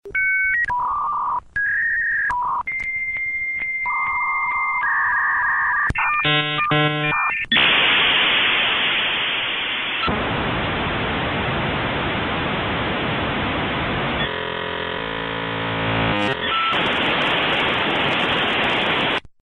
Знакомый шум установления соединения перенесет вас назад в 90-е. Все файлы доступны в высоком качестве для личного использования или творческих проектов.
Звук модема dial up